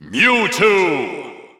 The announcer saying Mewtwo's name in English and Japanese releases of Super Smash Bros. 4 and Super Smash Bros. Ultimate.
Mewtwo_English_Announcer_SSB4-SSBU.wav